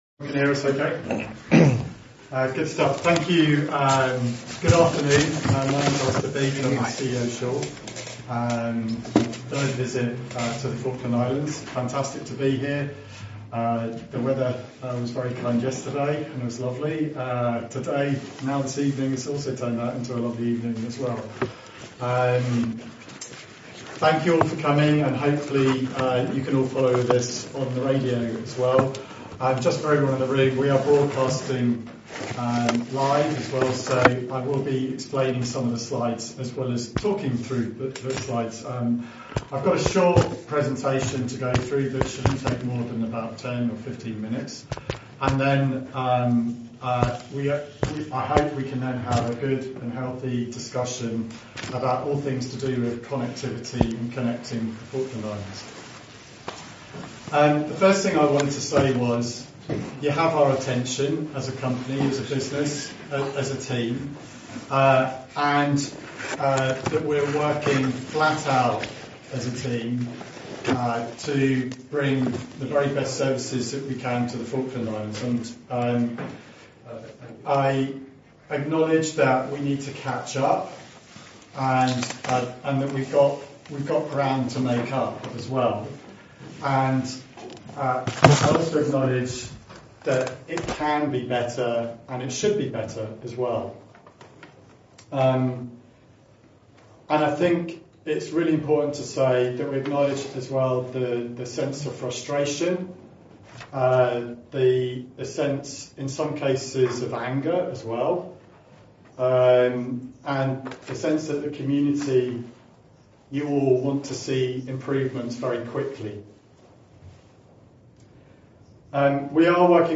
The public presentation audio.
This is a recording of the Sure public meeting as recorded in the meeting itself for best quality.
It’s best listened to with headphones.